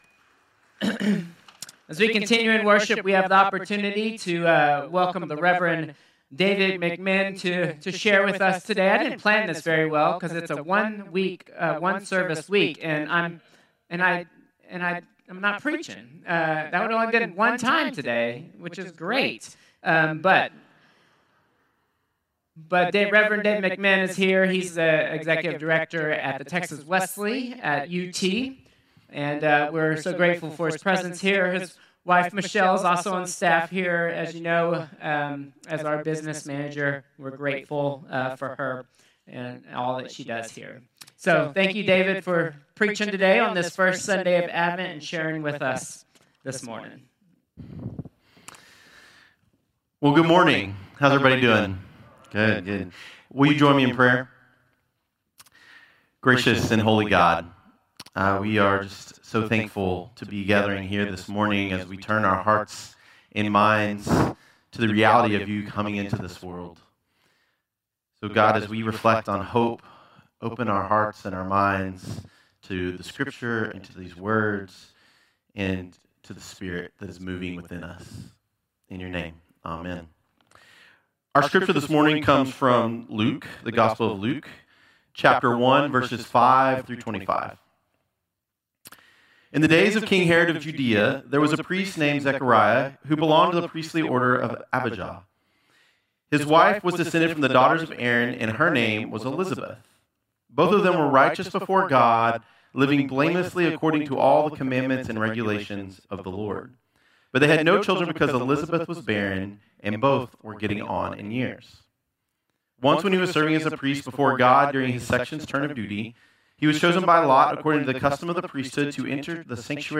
Combined Service 11/30/2025